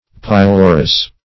Pylorus \Py*lo"rus\, n.; pl. Pylori.